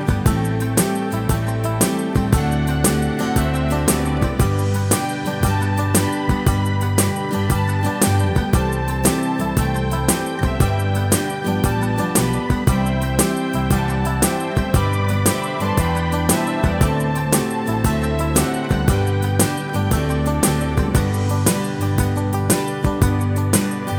No Backing Vocals Pop (1970s) 2:40 Buy £1.50